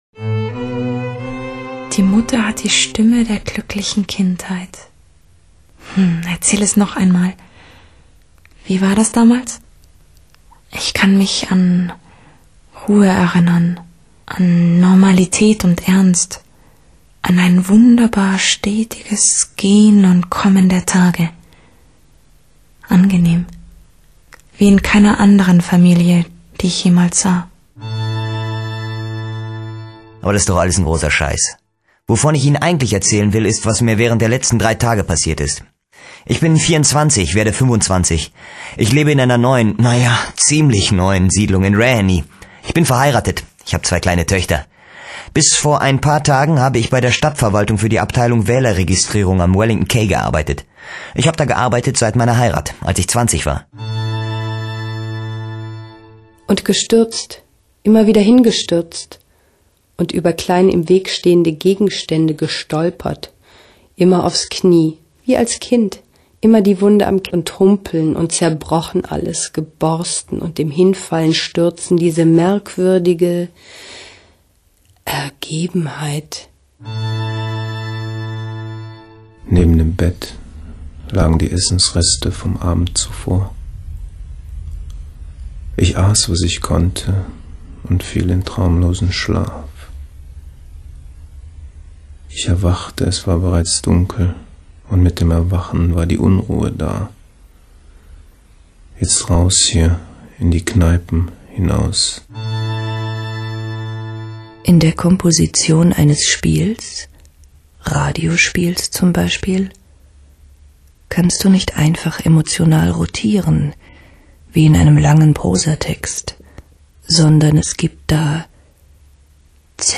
Hörbeispiele SPRACHE - TROCKEN Hörbeispiele SPRACHE - TROCKEN